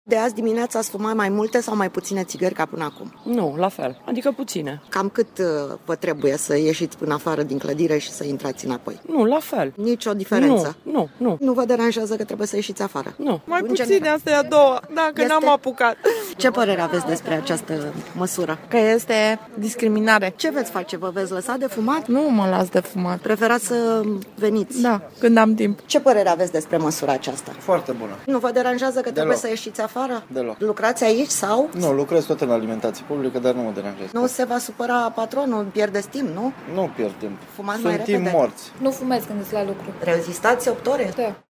Ce spun fumătorii din strada